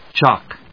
chock /tʃάktʃˈɔk/